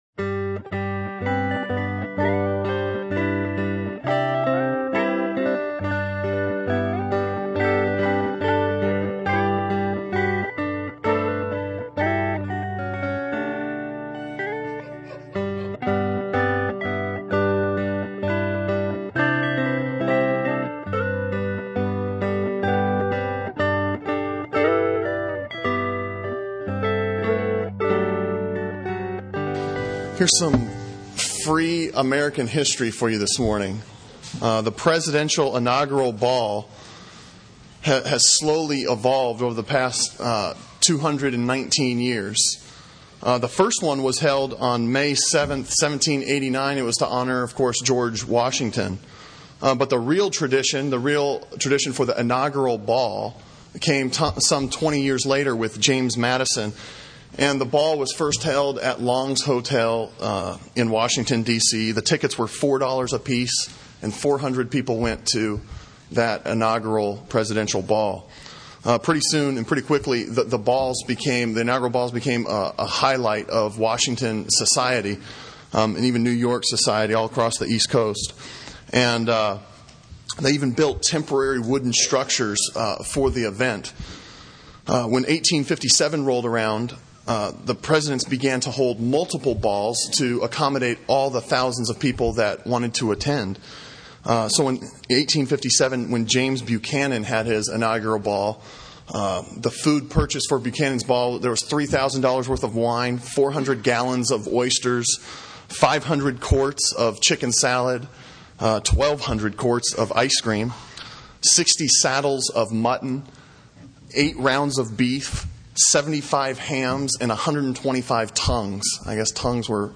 Sermon on Matthew 16:21-28 from February 17